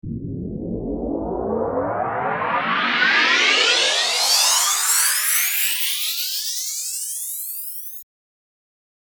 Download Riser sound effect for free.
Riser